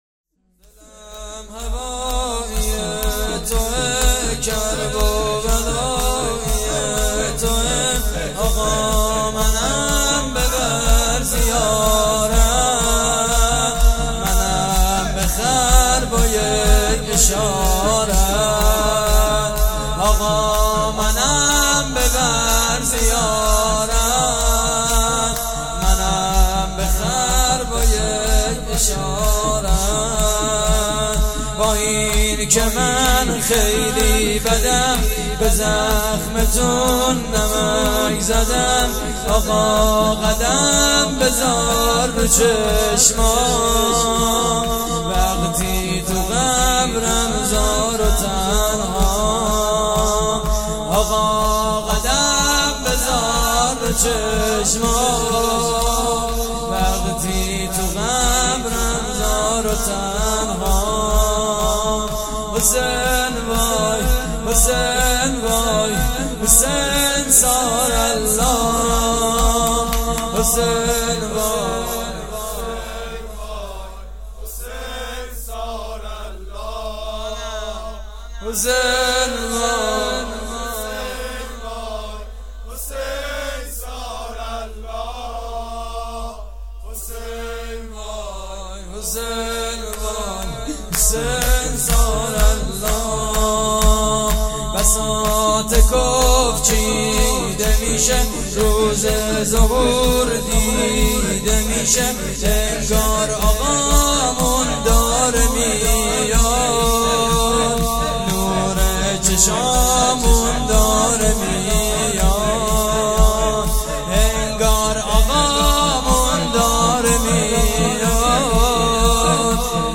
شور: دلم هوایی توئه
مراسم عزاداری شهادت امام موسی کاظم (ع)